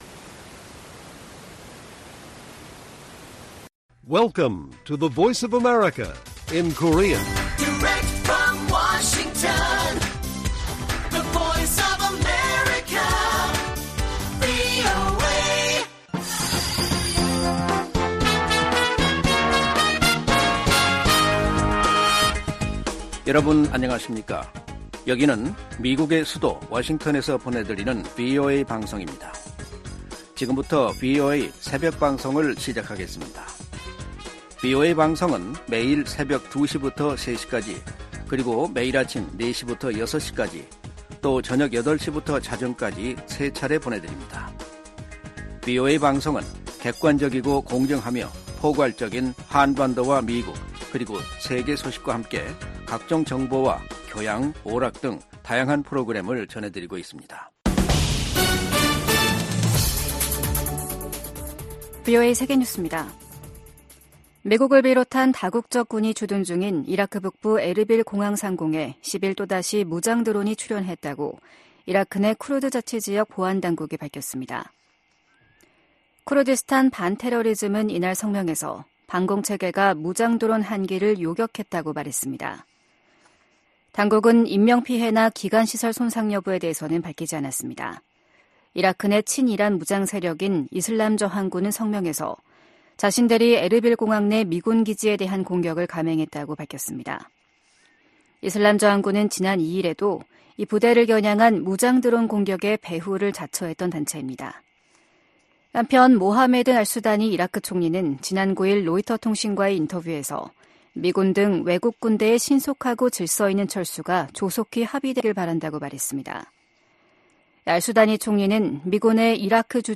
VOA 한국어 '출발 뉴스 쇼', 2024년 1월 12일 방송입니다. 백악관은 팔레스타인 무장정파 하마스가 북한 무기를 사용한 사실을 인지하고 있다고 밝혔습니다. 미국, 한국, 일본 등이 유엔 안보리 회의에서 러시아가 북한에서 조달한 미사일로 우크라이나를 공격하고 있는 것을 강력하게 비판했습니다.